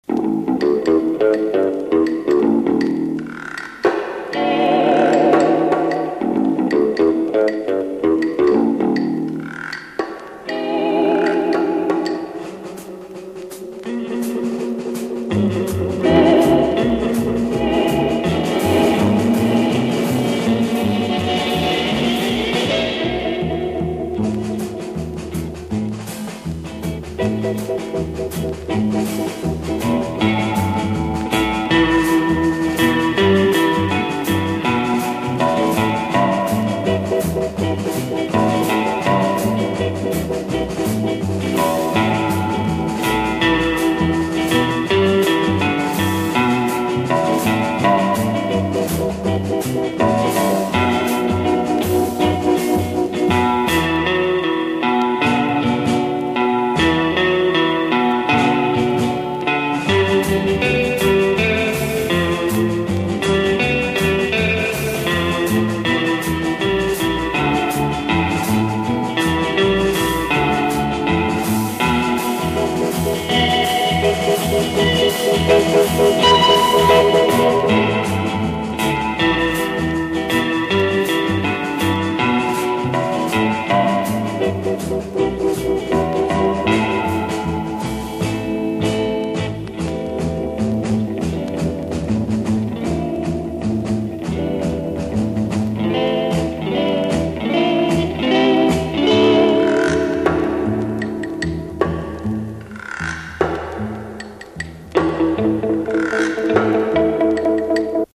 Не получается опознать гитарный инсамбль, который похож на "The Shadows".
веселенько - загодачная гитараная игра лента кончается_обрыв.mp3